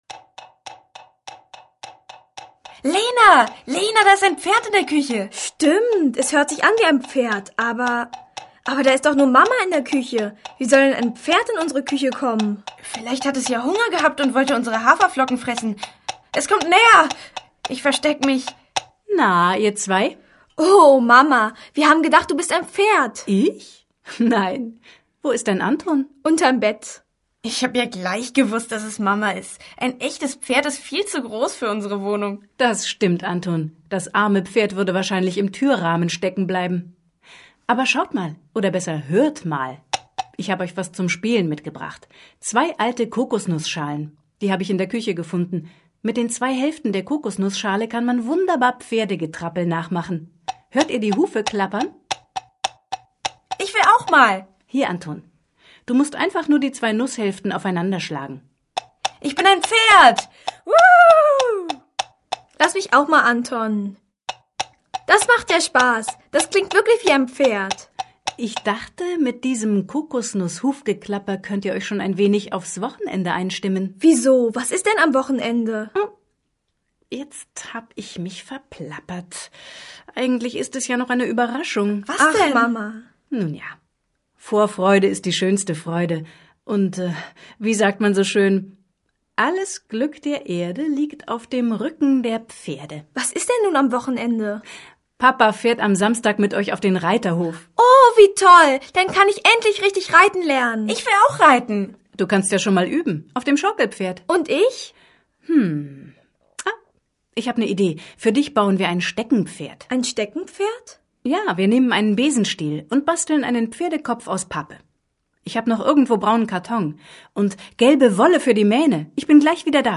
Und dass es auf einem Reiterhof nicht nur Pferdegetrappel zu hören gibt, sondern auch Geräusche von anderen Tieren, das Rattern eines Planwagens, das Quietschen der Stalltüren und vieles mehr - das wissen Anton und Lena nach diesem spannenden Ausflug jetzt auch.